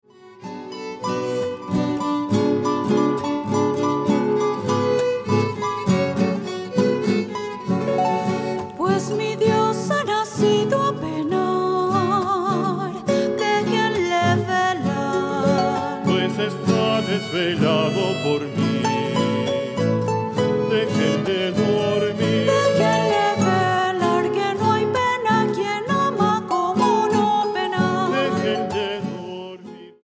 Versiones mariachi